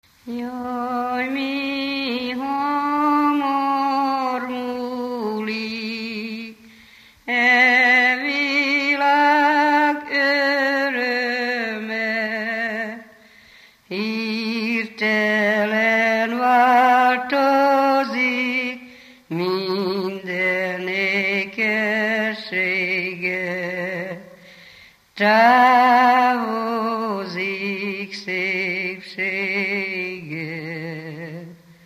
Alföld - Bihar vm. - Csökmő
Műfaj: Virrasztó ének
Stílus: 4. Sirató stílusú dallamok
Szótagszám: 12.12.6